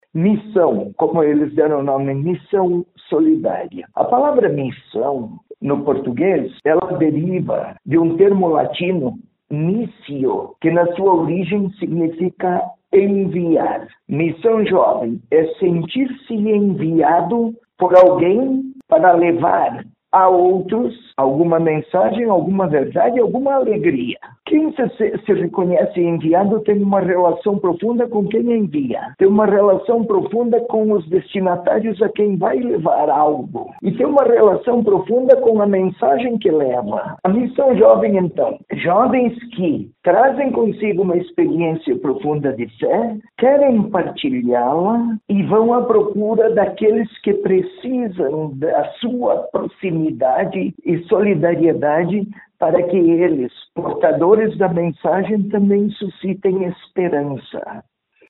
O arcebispo metropolitanoDom Peruzzo, contou sobre a história das celebrações.